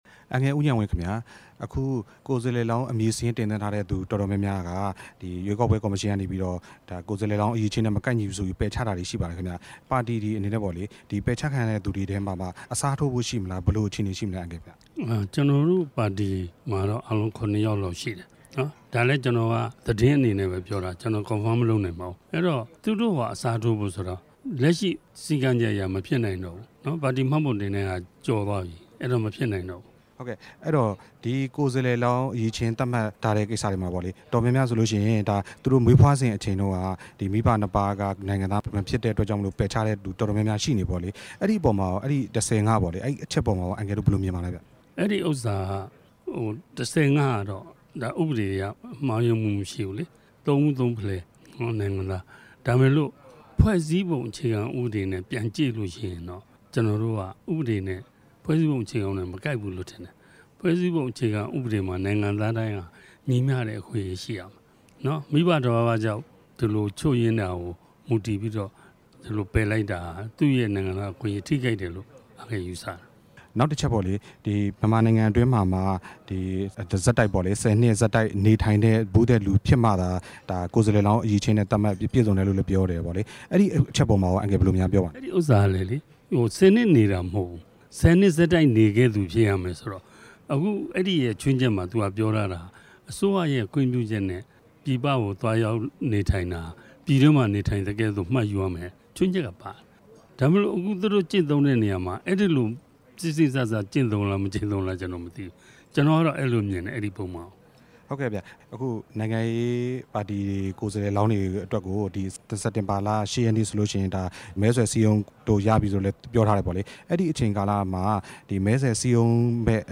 ဒီနေ့ ရန်ကုန်မြို့ Park Royal ဟိုတယ်မှာပြုလုပ်တဲ့ ပြည်ထောင်စု ရွေးကောက်ပွဲကော်မရှင်နဲ့ နိုင်ငံရေး ပါတီတွေရဲ့ ဆွေးနွေးပွဲအပြီးမှာ RFA ကို ပြောလိုက်တာဖြစ်ပါတယ်။